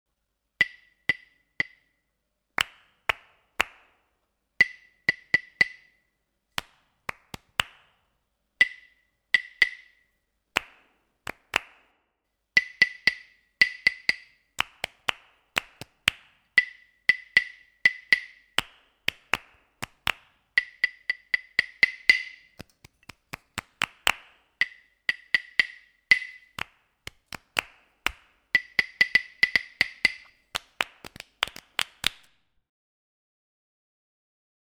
3. Klapoefening 1